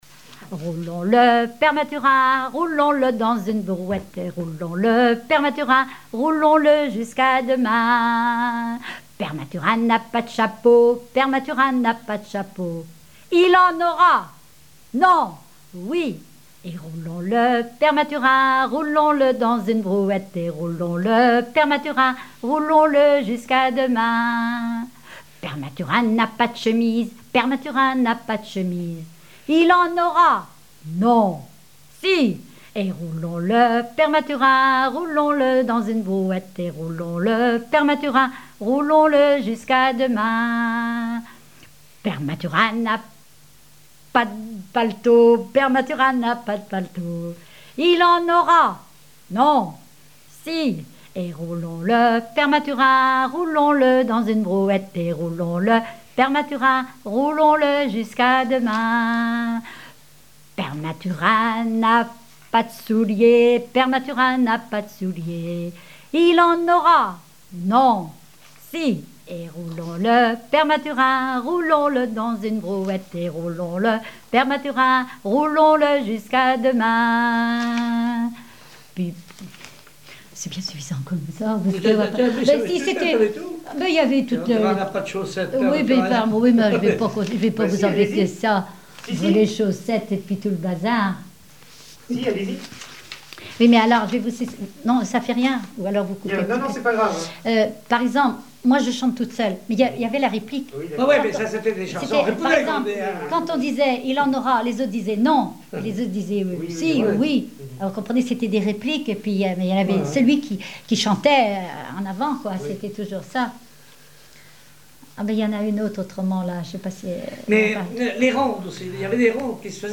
danse : ronde
chansons populaires et traditionnelles
Pièce musicale inédite